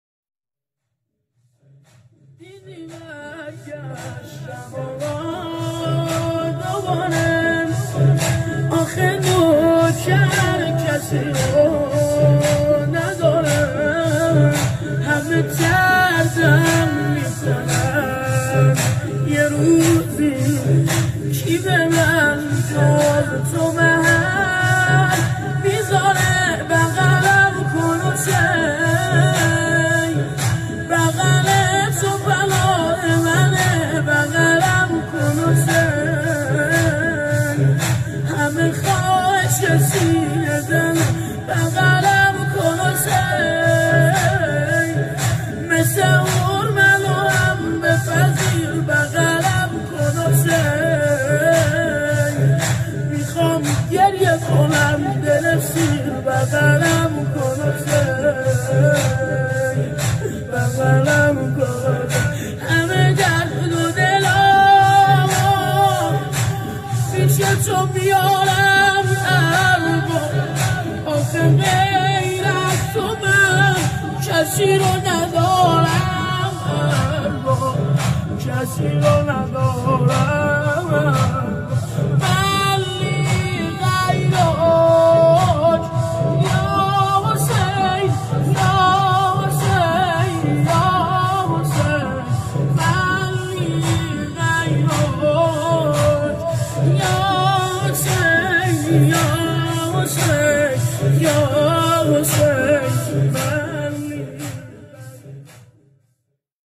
دانلود نماهنگ و مناجات دلنشین
نماهنگ دلنشین